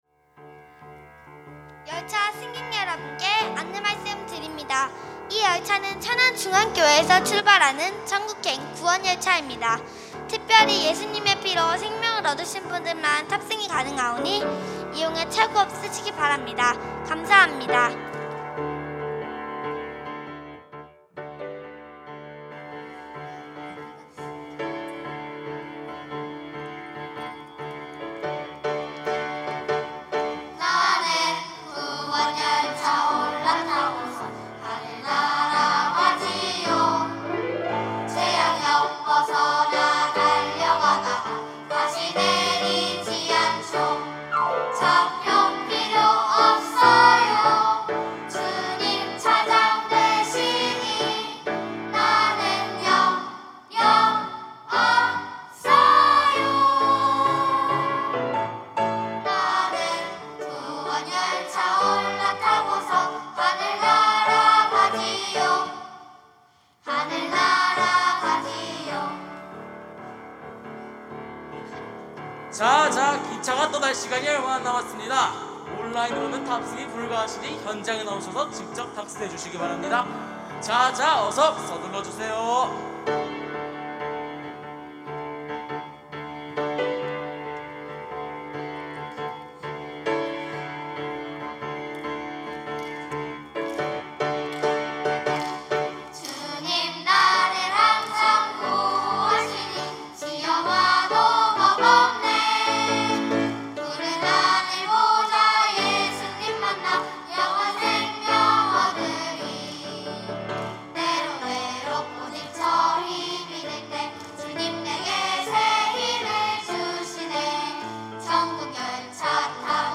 특송과 특주 - 구원열차
천안중앙 유스콰이어